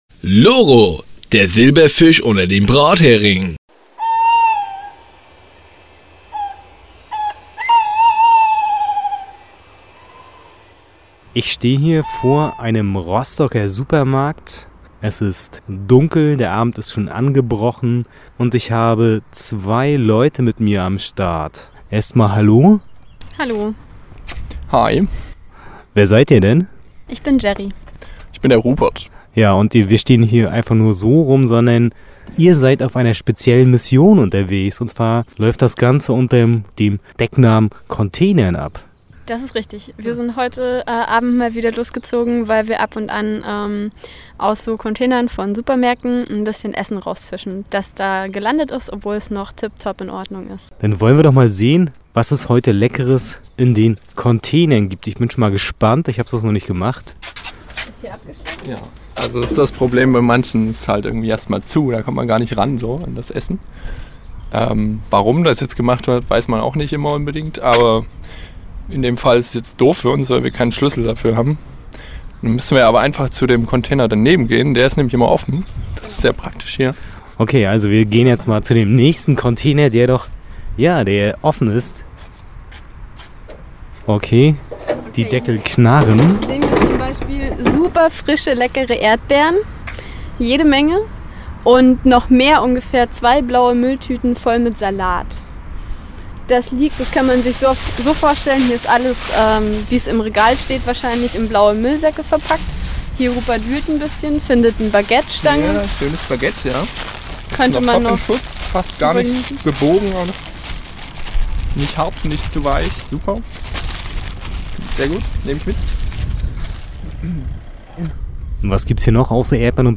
zwei Mülljagende bei ihrem Streifzug begleitet.